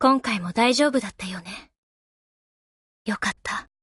Standard Voices